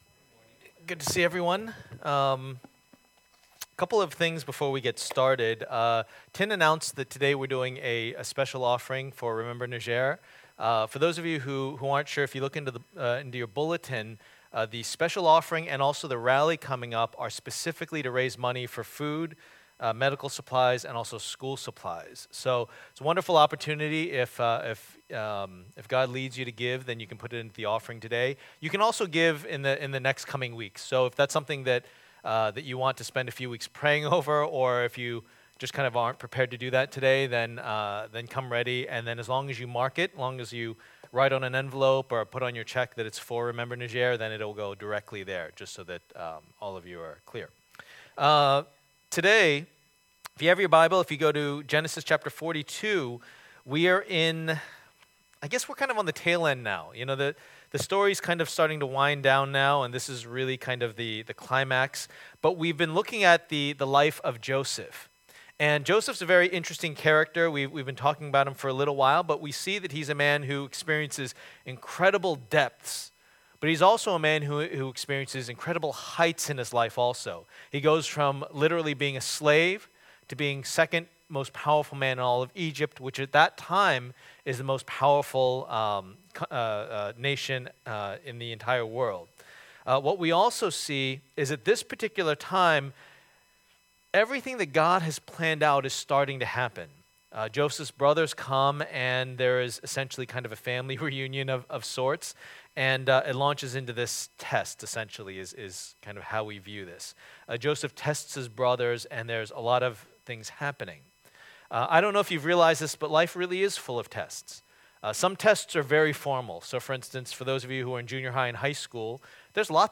Passage: Genesis 42:1-28 Service Type: Lord's Day